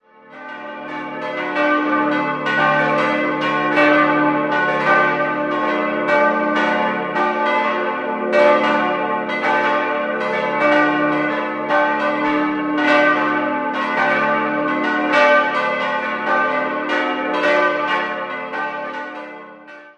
4-stimmiges Salve-Regina-Geläute: d'-fis'-a'-h'